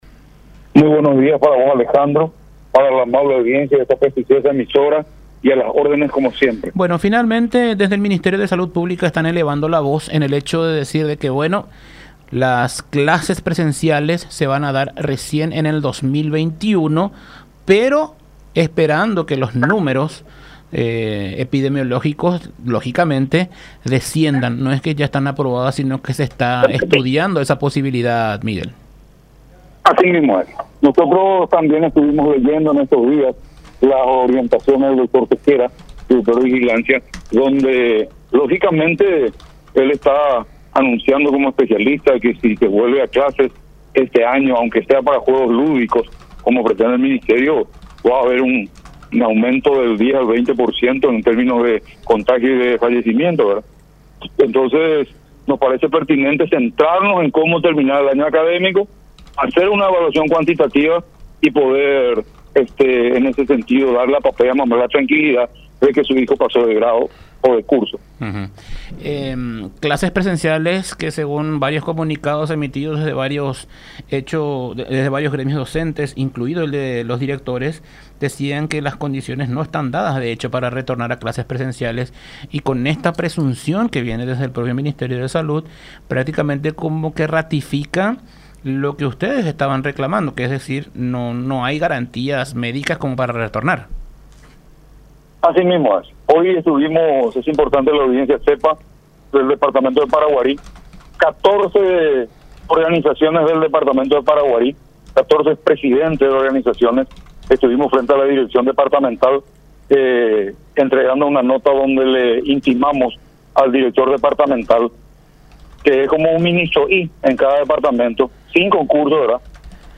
en diálogo con La Unión R800 AM.